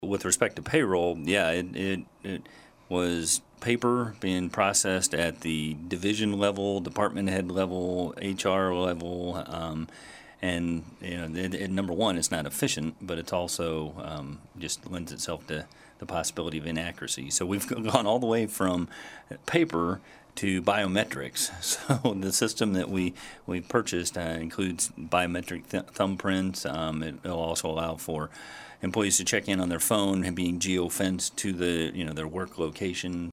Salina City Manager Mike Schrage appeared on the KSAL Morning News Extra with a look back at the investigation – and how that issue is being handled now.